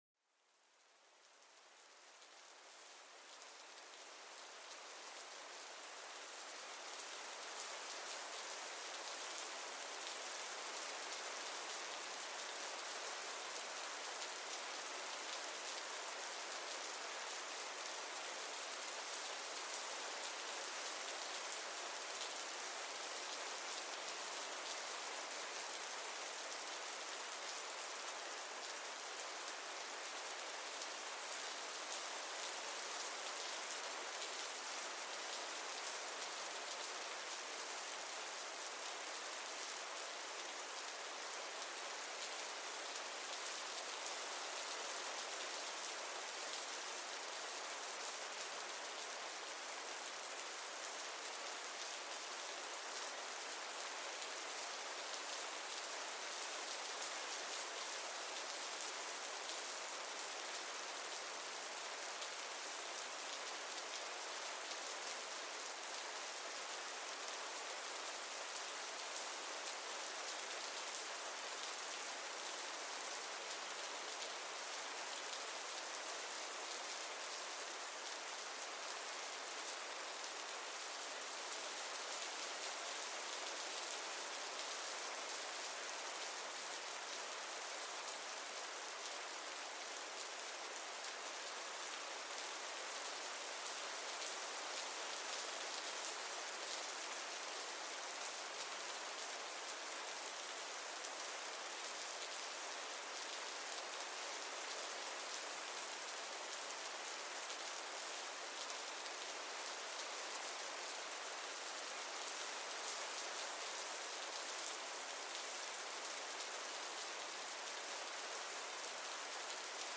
Déjate envolver por el sonido reconfortante de un chaparrón, donde cada gota añade una nota a esta melodía relajante.
Cierra los ojos e imagínate bajo un cielo lluvioso, donde el sonido de la lluvia se convierte en una fuente de serenidad y renovación.Este podcast es una experiencia de audio inmersiva que sumerge a los oyentes en los maravillosos sonidos de la naturaleza.